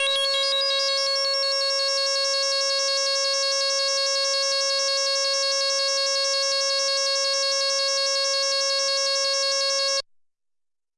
标签： CSharp6 MIDI音符-85 罗兰-JX-3P 合成器 S英格尔音符 多重采样
声道立体声